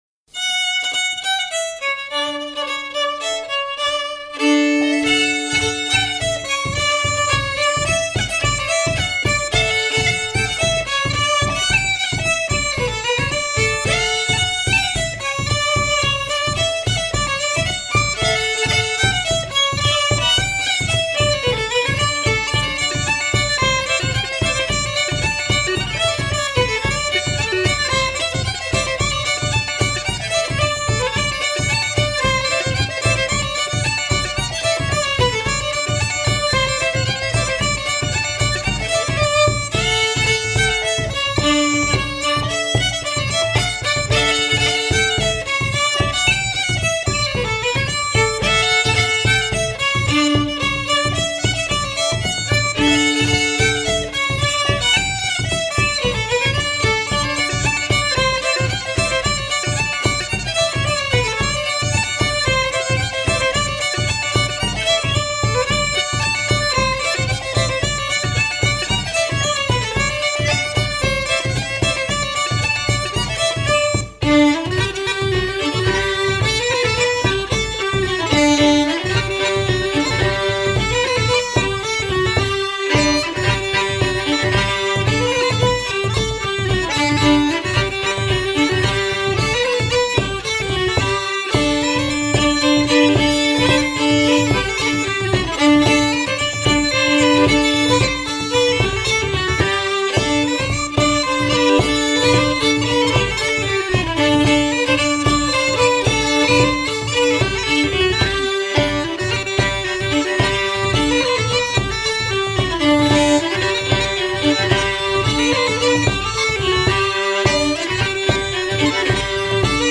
Fiddle, Pipe & Drum :: Celtic Music
Together they play exhilarating combinations of jigs, reels, polkas, slides and slow airs, which reflect the variety of their individual styles and backgrounds, truly “music to lift the soul”.
(Fiddle, Uillean Pipes & Bodhran )